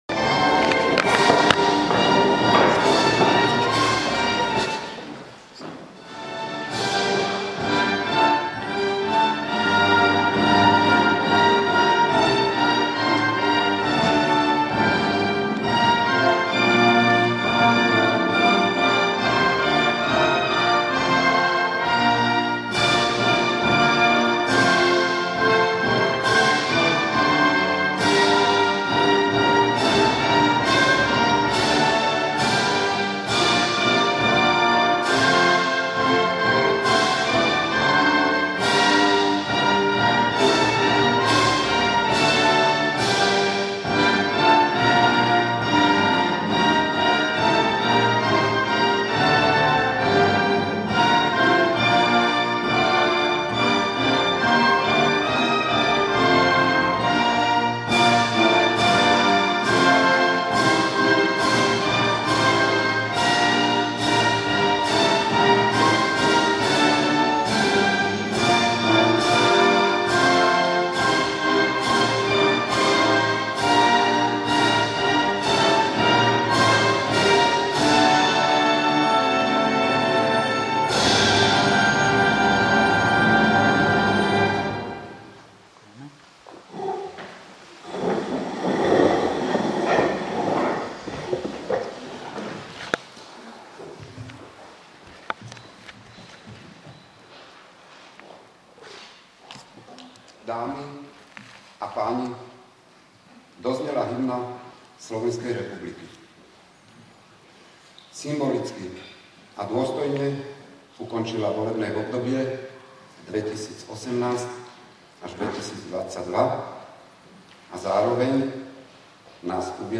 Borský Mikuláš - Portál elektronických služieb | Elektronické služby | Registre | Zvukový záznam z 1. zasadnutia OZ